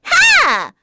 Pansirone-Vox_Attack4_kr.wav